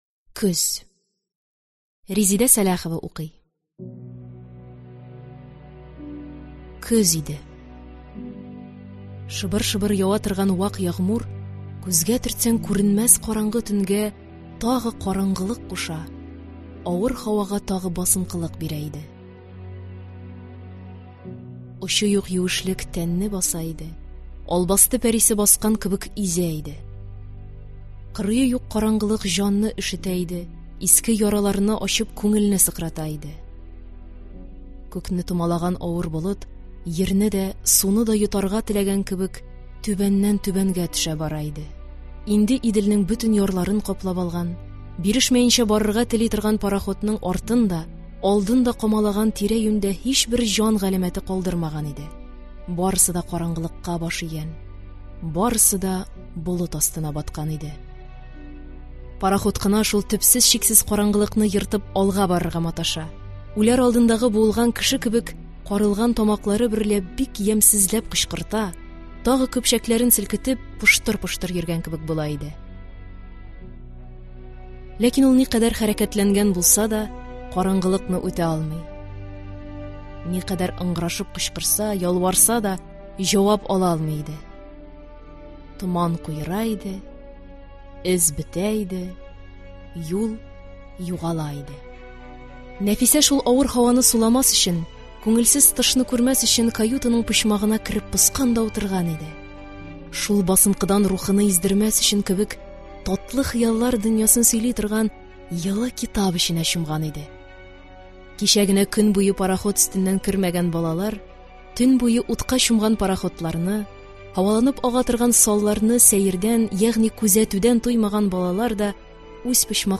Аудиокнига Көз | Библиотека аудиокниг